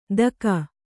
♪ daka